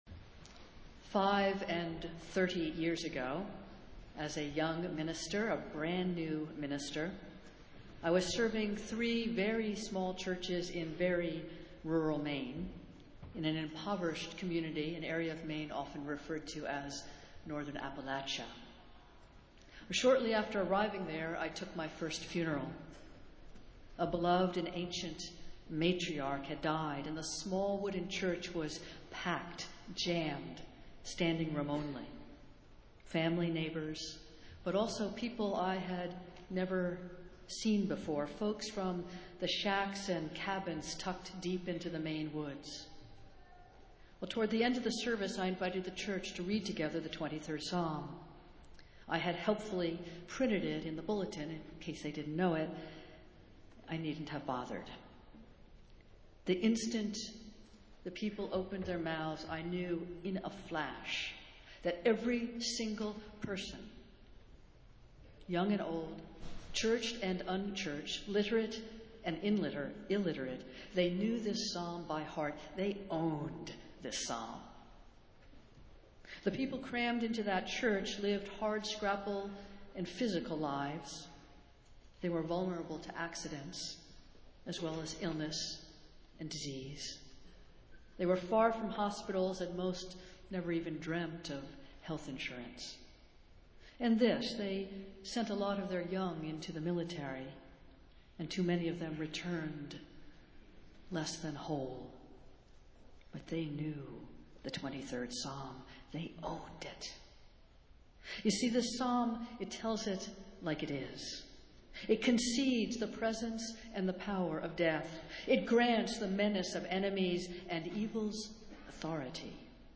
Festival Worship - Marathon Sunday